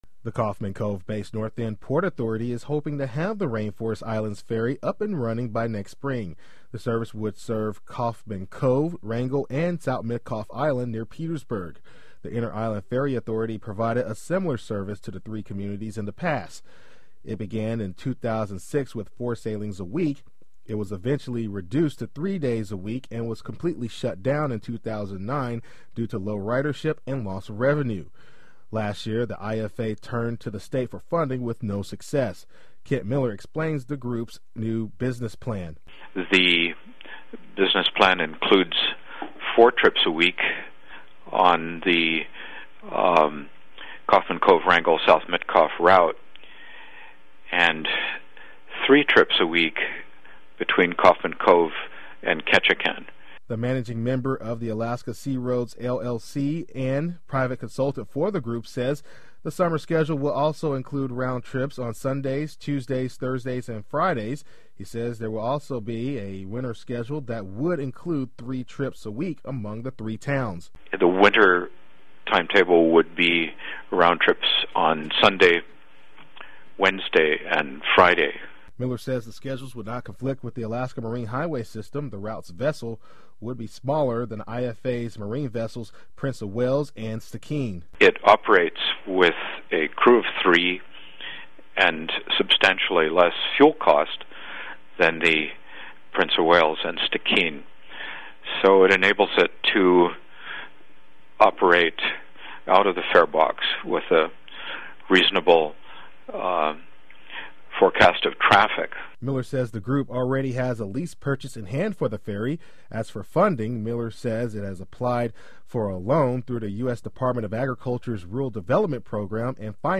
Did you appreciate this report?